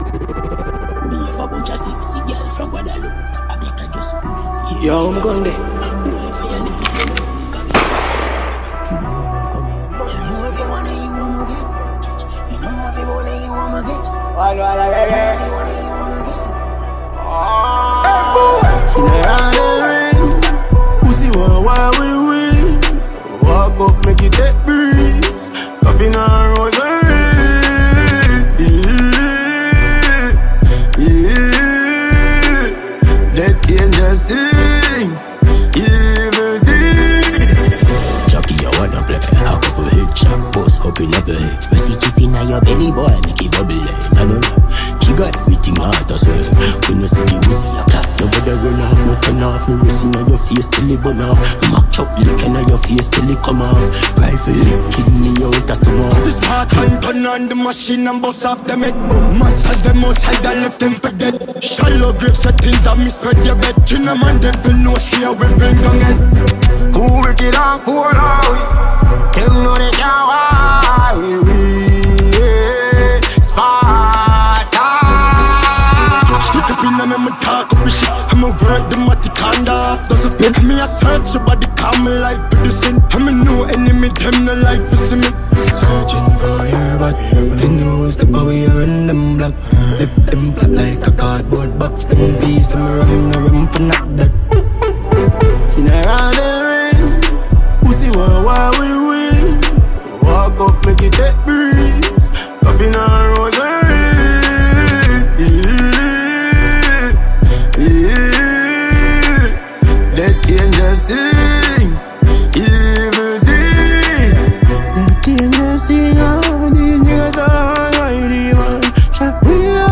Jamaican dancehall
Caribbean